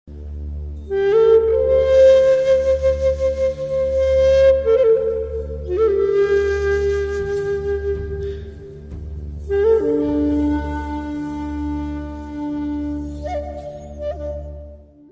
Orientalische Flöte Shakuhachi (pentathonisch)
Sie hat auch fünf Töne, um den orientalischen „Geist“ spüren zu lassen.
Meine Flöten wurden elektronisch gestimmt.
Oriental_sound.mp3